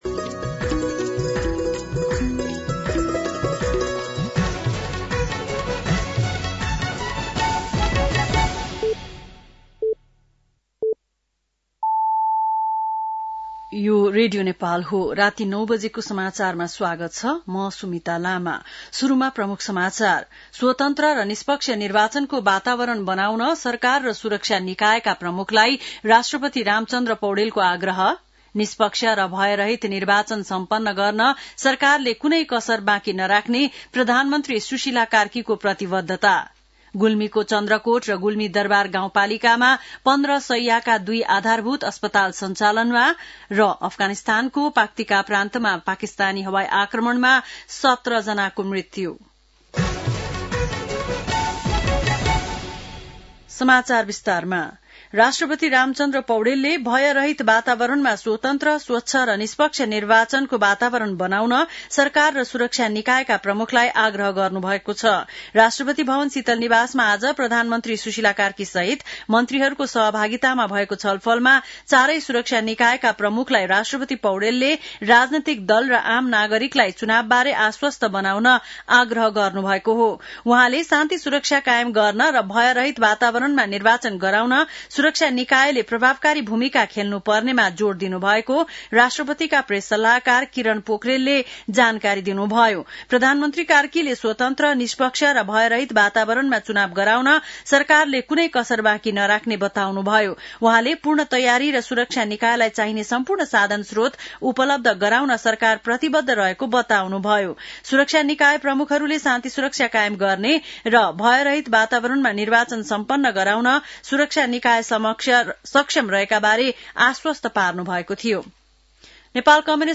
बेलुकी ९ बजेको नेपाली समाचार : १ कार्तिक , २०८२